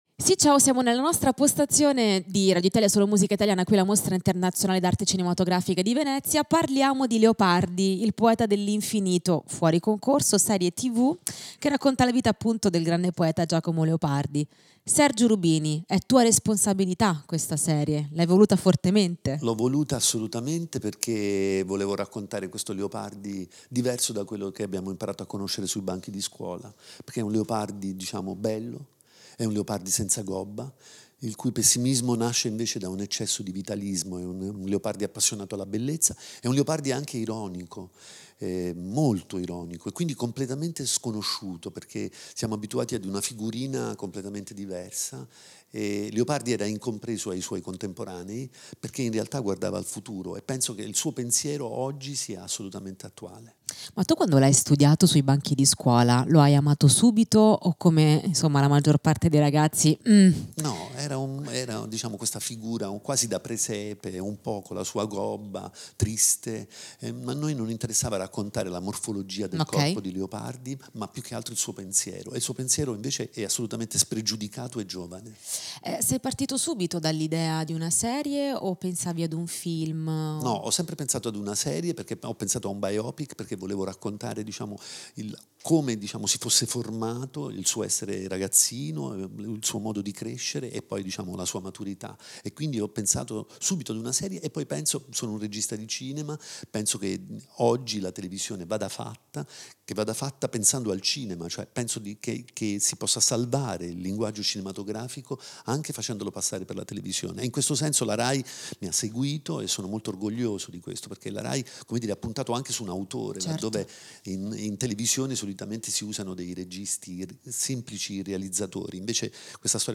Intervista a Sergio Rubini (Leopardi) del 30/08/2024